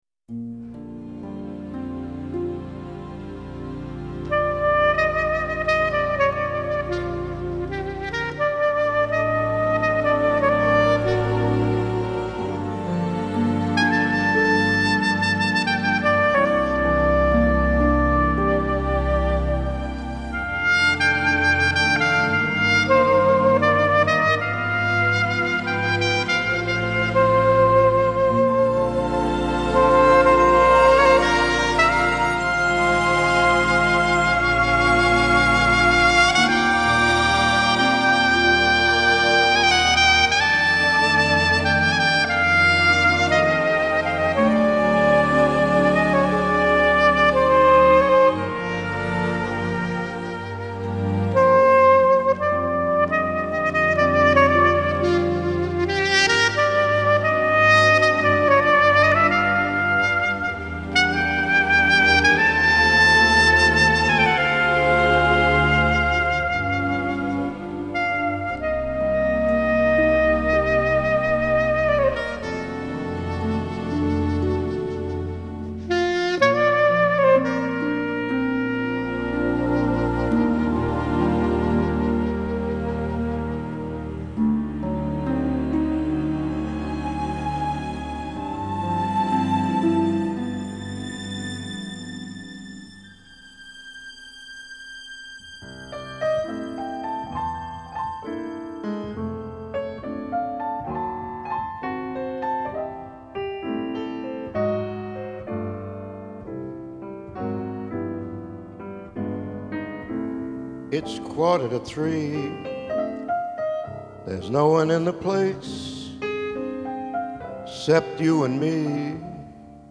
Duet medley